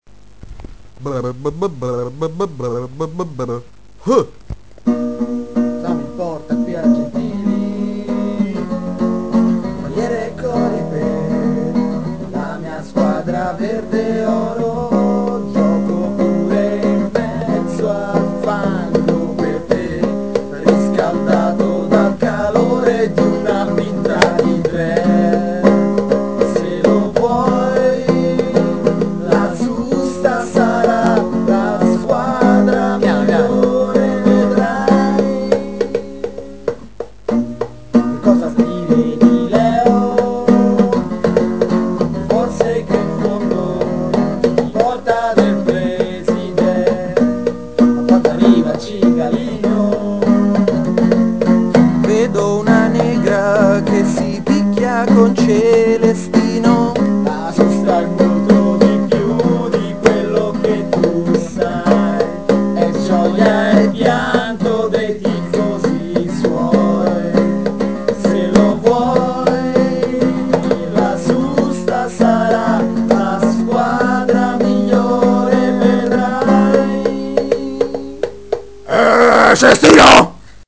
coro
alla chitarra
al tamburello